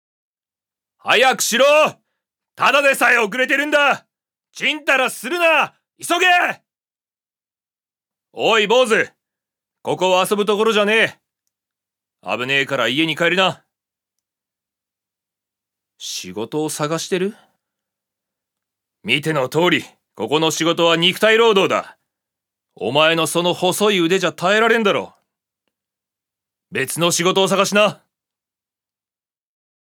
所属：男性タレント
セリフ１